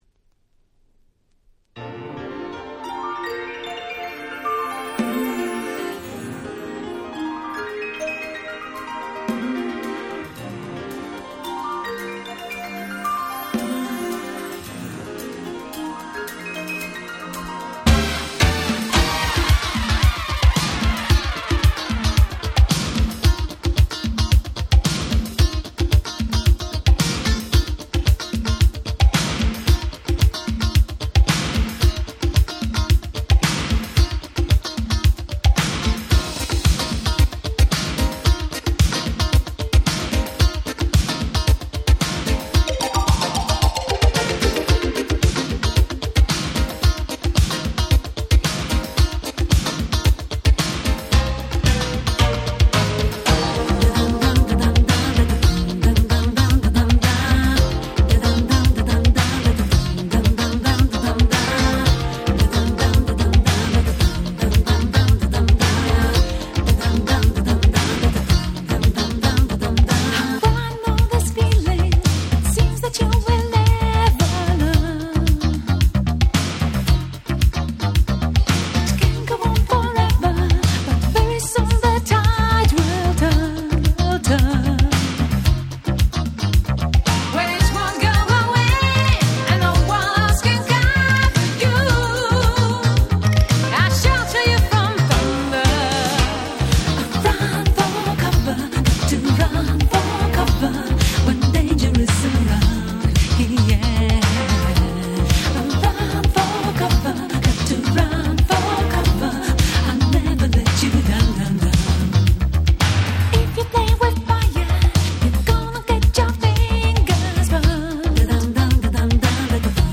86' Smash Hit Pops !!
軽快でキャッチーな80's Popsナンバーです。